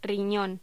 Locución: Riñón
voz
Sonidos: Voz humana